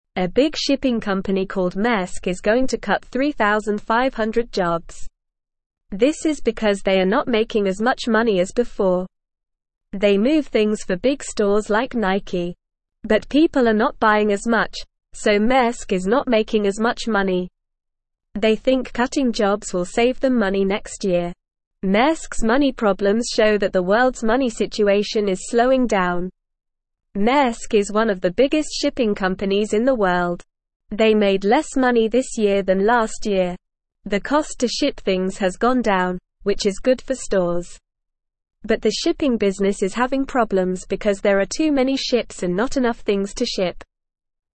Normal
English-Newsroom-Beginner-NORMAL-Reading-Big-Shipping-Company-Maersk-Cuts-Jobs-Due-to-Money-Problems.mp3